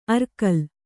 ♪ arkal